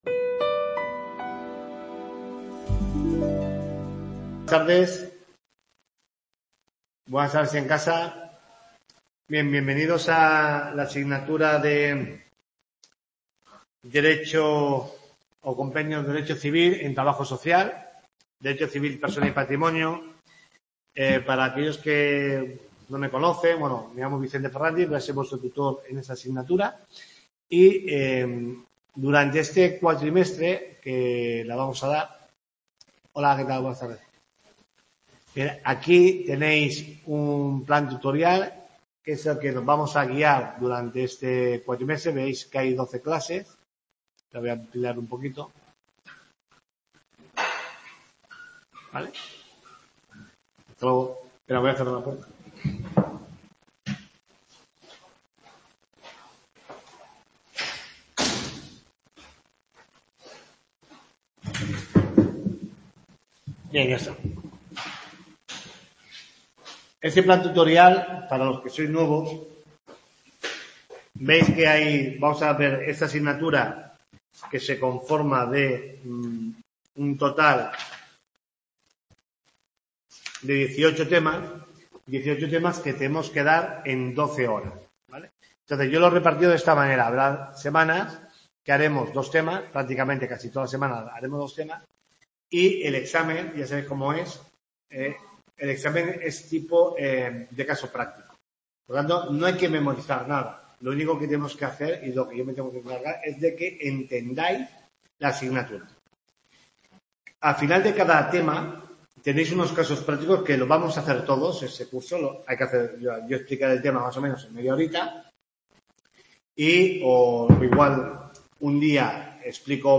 TUTORIA 1